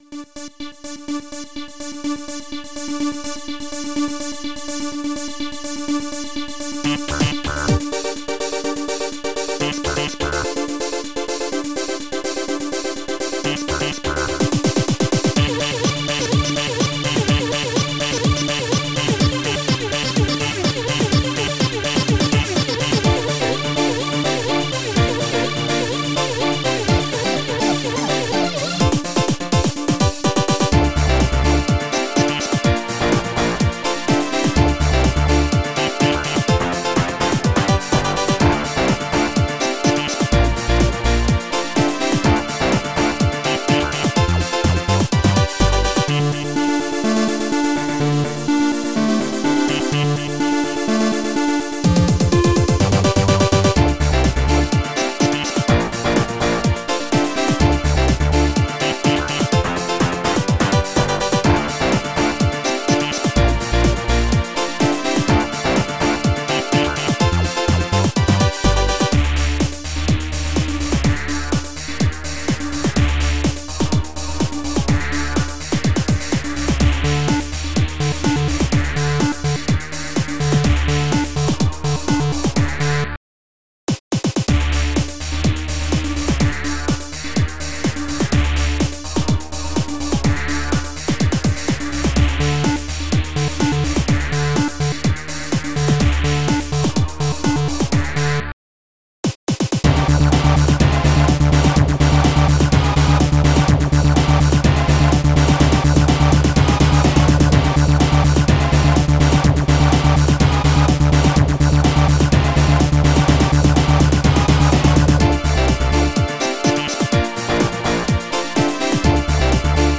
bleep
bassdrumhihat
bass
piano2